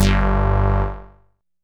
synth note01.wav